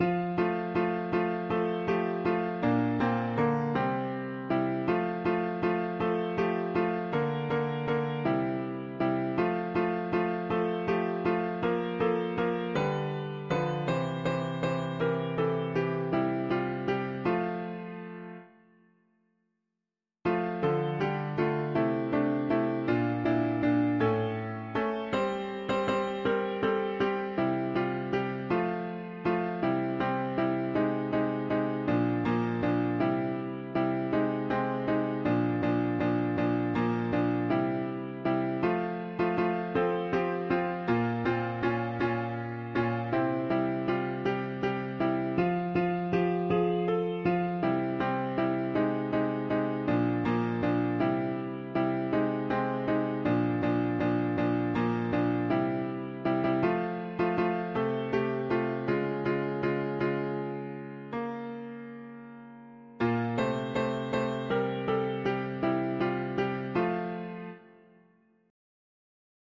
When harvest day comes, after all that abuse, the … english secular 3part chords